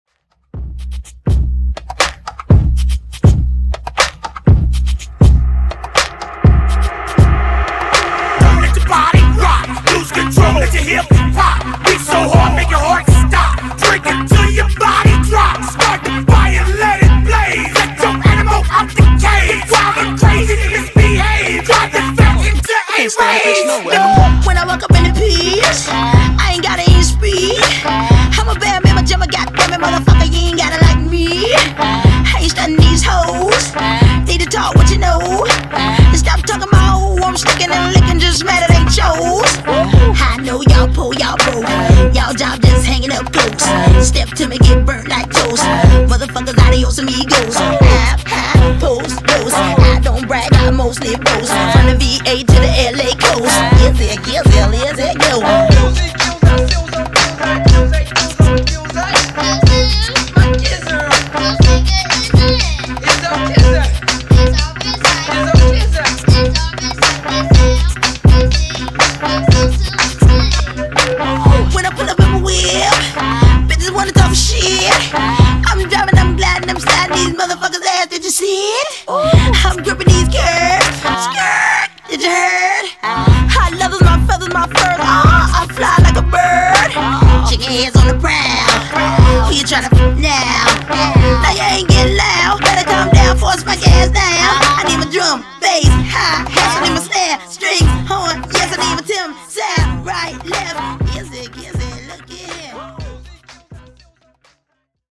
Genres: 80's , RE-DRUM
Clean BPM: 100 Time